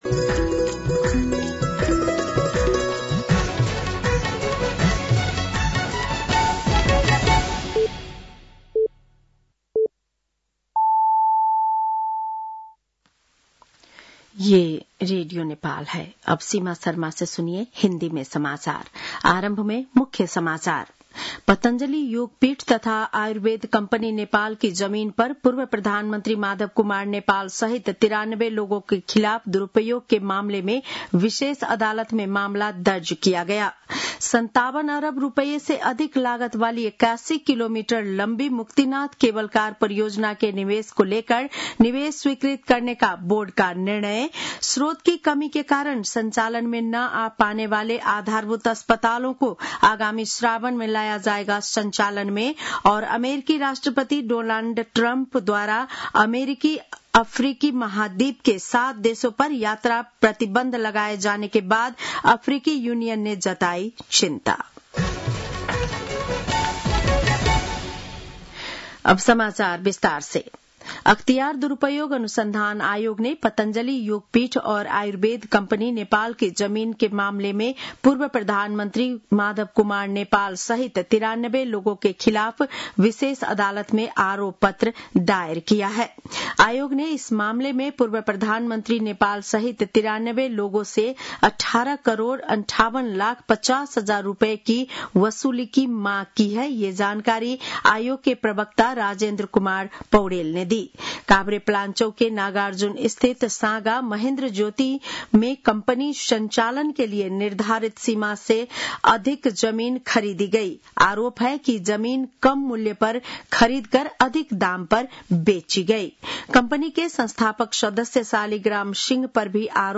बेलुकी १० बजेको हिन्दी समाचार : २२ जेठ , २०८२
10-PM-Hindi-NEWS-2-22.mp3